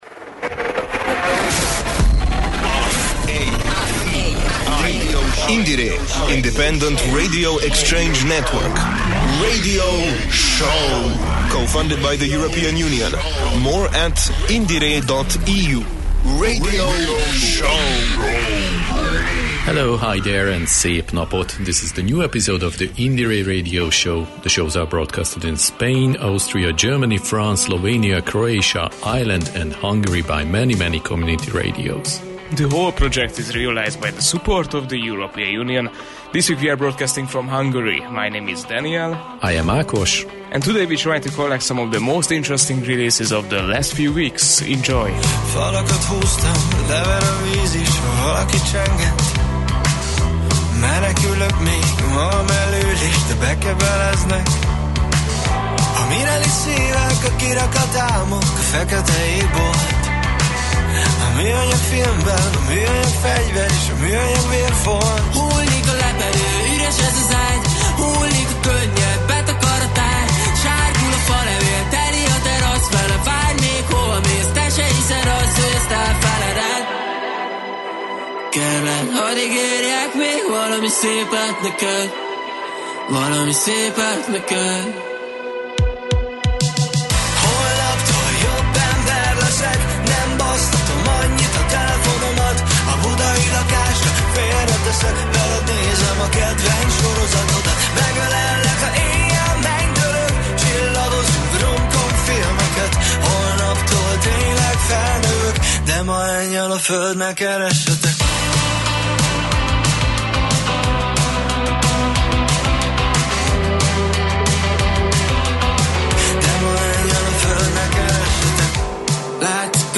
Our journey takes us from alternative rock to psychedelic directions.